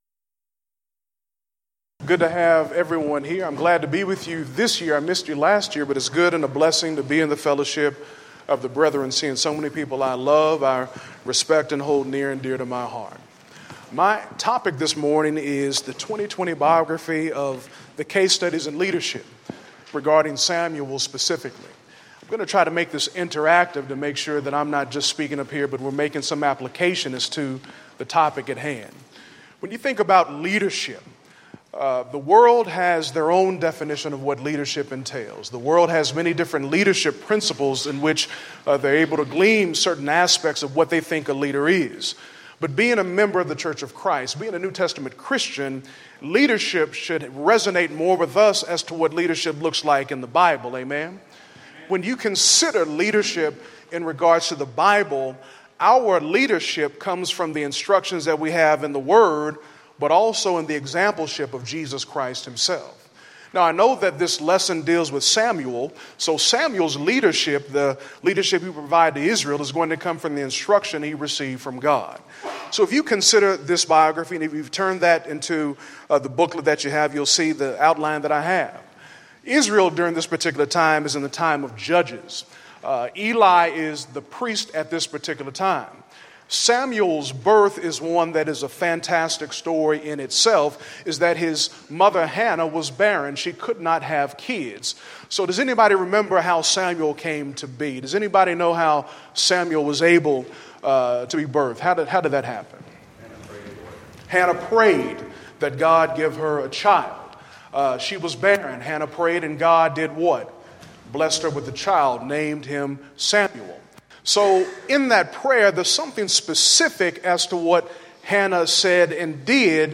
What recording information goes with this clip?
Event: 5th Annual Men's Development Conference